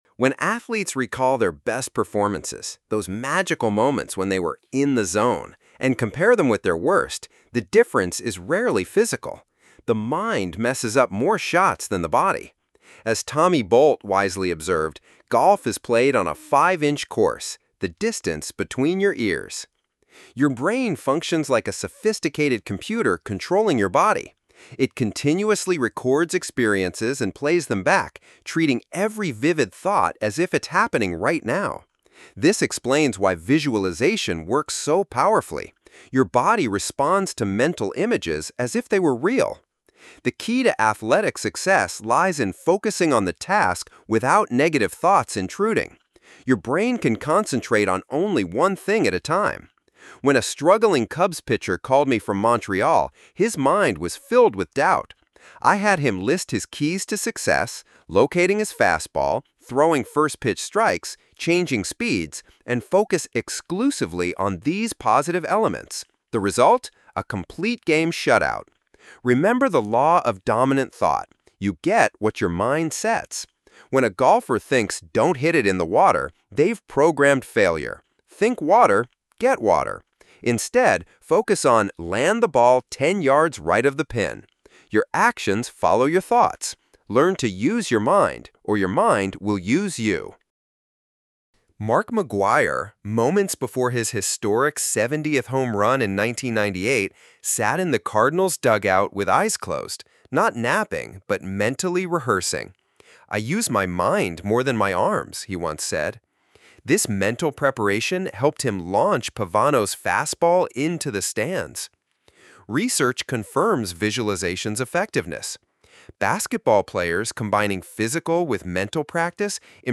Mind gym Summary in 9 Minutes